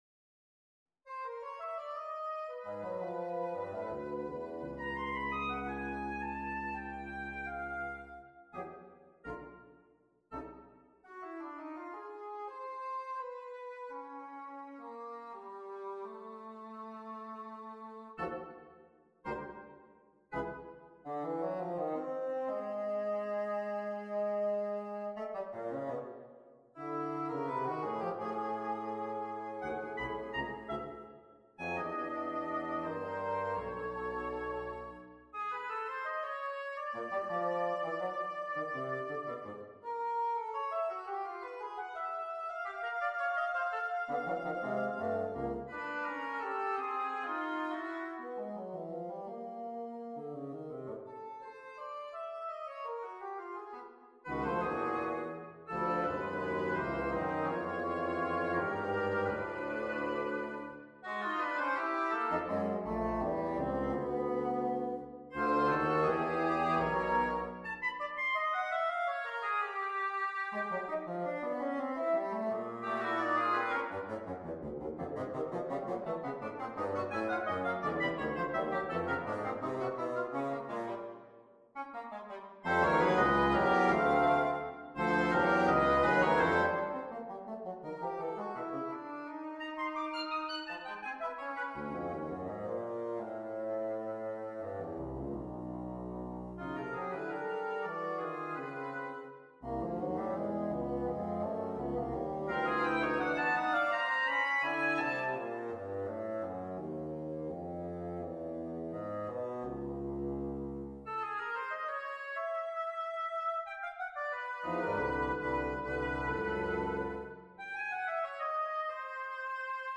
on a purpose-selected tone row
Allegro molto - Presto - Tempo Primo - Prestissimo - Tempo Primo
OK, despite the fact that the material in this little essay undegoes continual tonal center and thematic variation throughout, a regrettable paucity of rhythmic contrast in places may be noticeable.